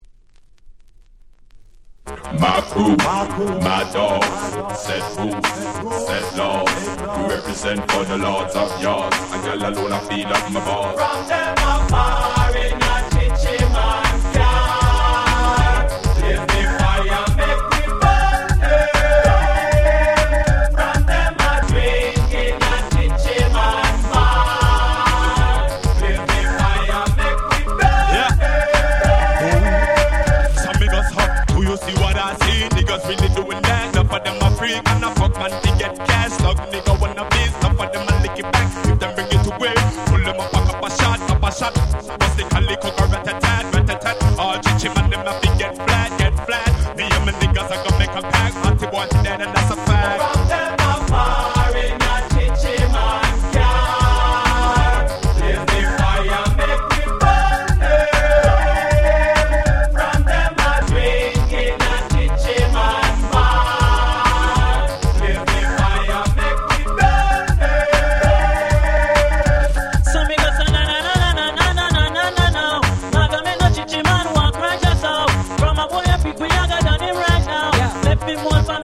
White Press Only Remix / Mash Up !!
Dancehall Reggae